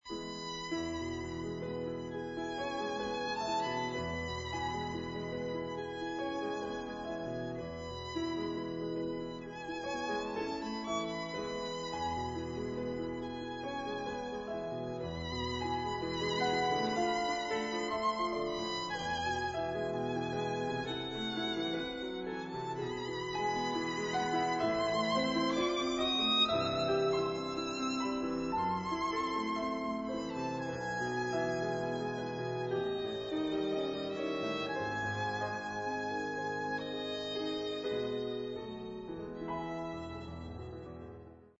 violin solo